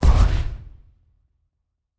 Sci Fi Explosion 10.wav